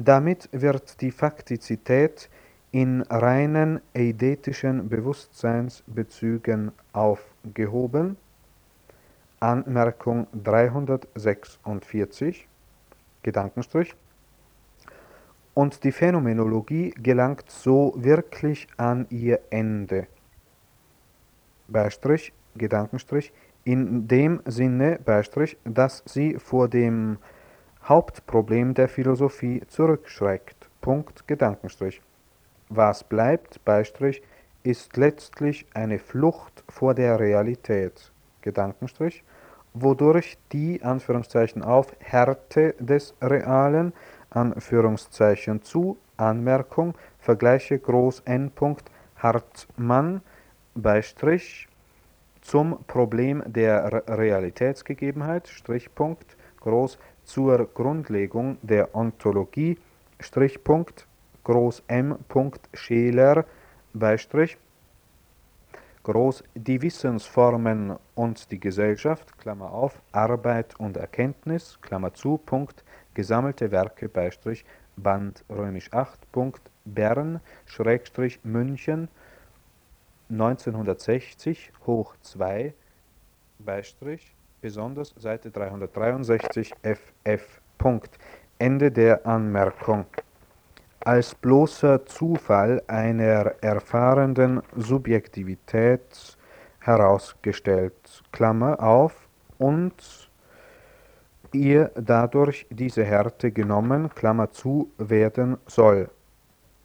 "The End of Phenomenology": audio clip / dictation (1970) of doctoral dissertation,